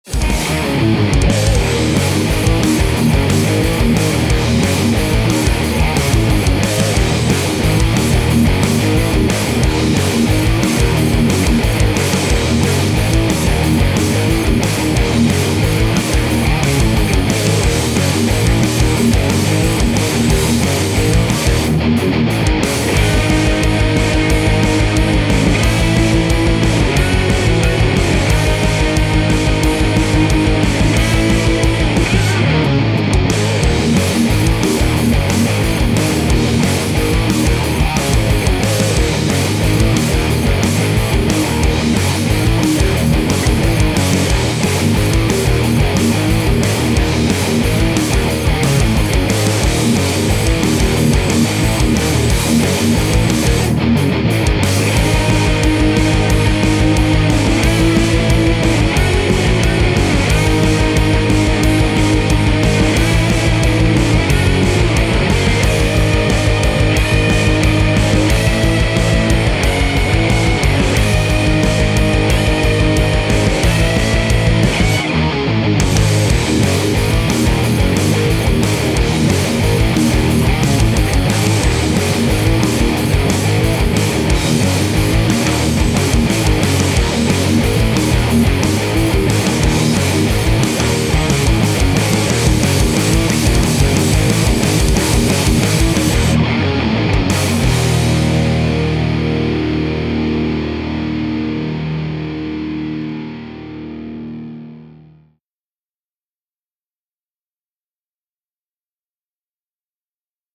【用途/イメージ】　アクションシーン　筋トレ動画　激しさ　重たさ
BPM180　重たさを強調したギターロック